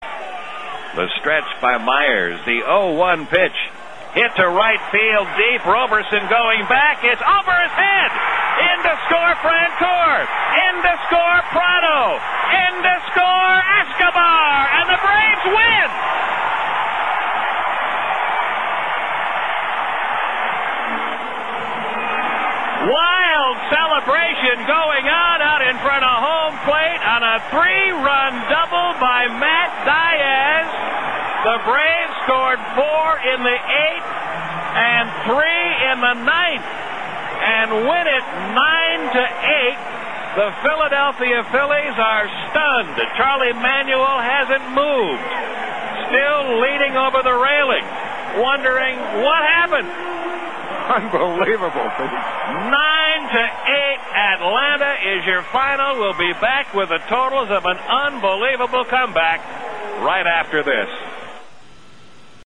Pete Van Wieren with the call.